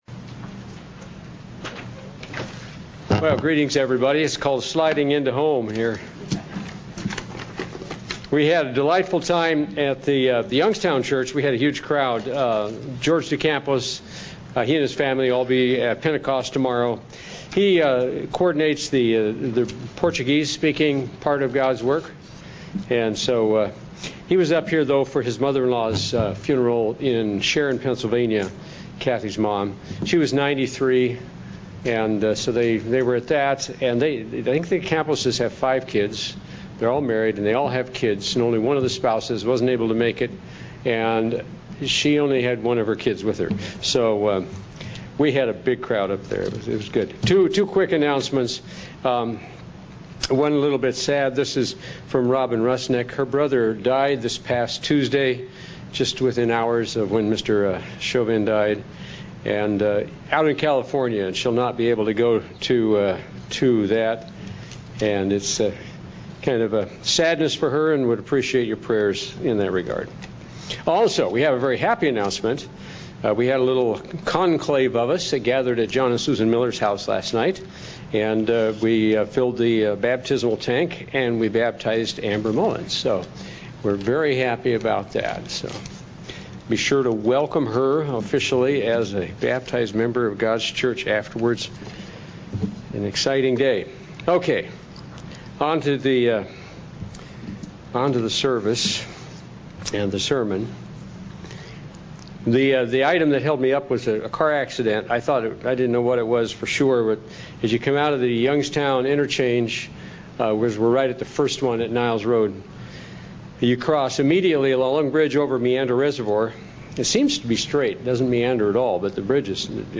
Sermon
Given in North Canton, OH